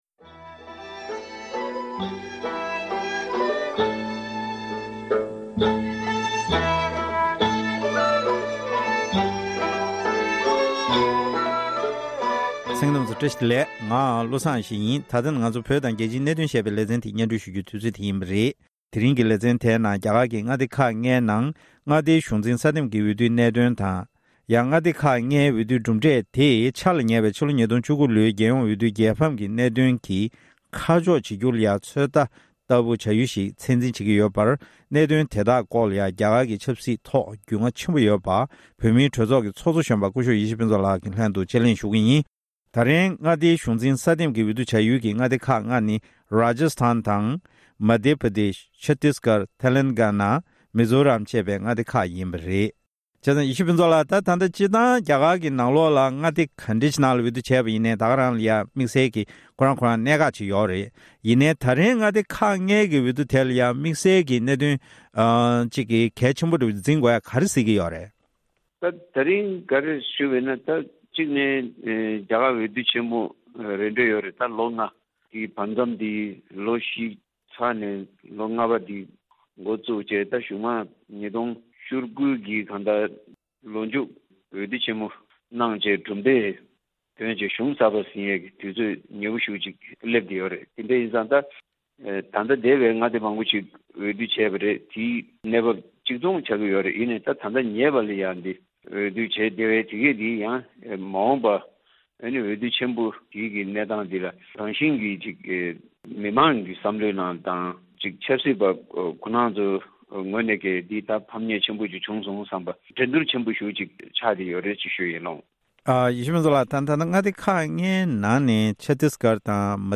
དཔྱད་གླེང་ཞུས་པ་དེར་གསན་རོགས་ཞུ༎